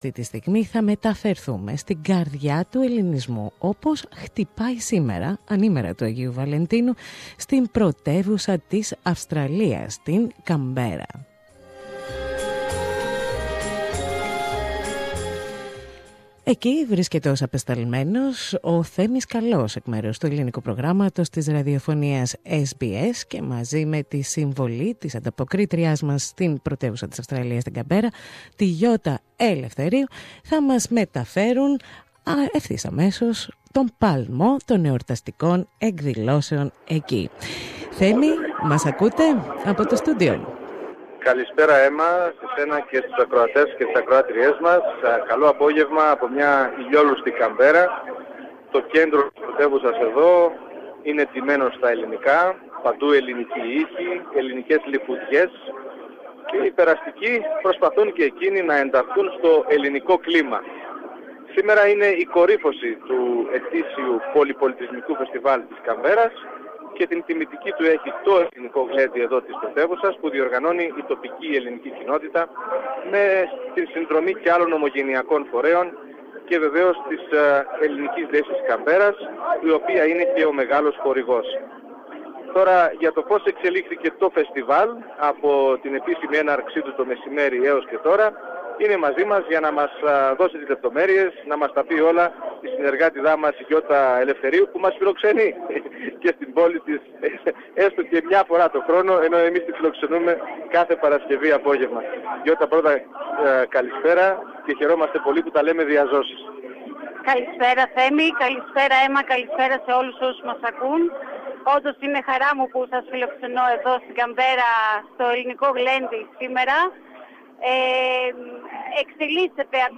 With great participation and success the Greek "glenti" took place on Valentine's day in Canberra, the Australian capital.